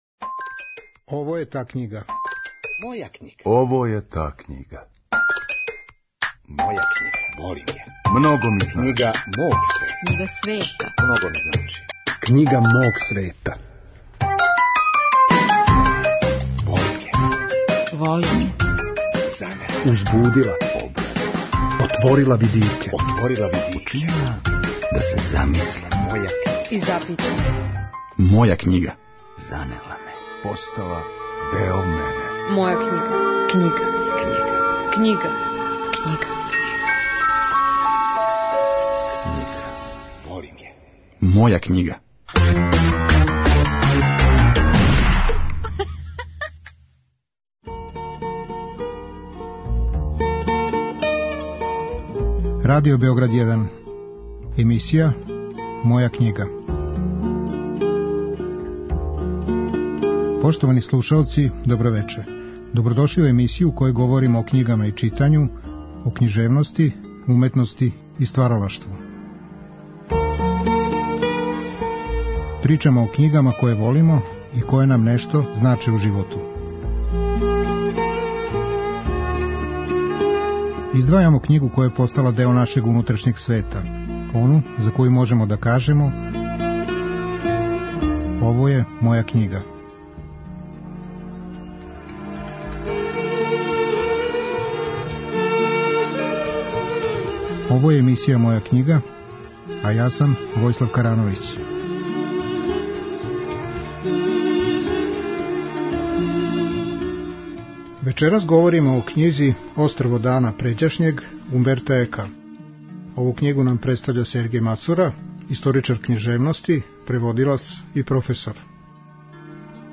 Наш саговорник говори о томе како је открио ово дело, зашто га је заволео, и зашто му је оно постало омиљена књига којој се често враћа.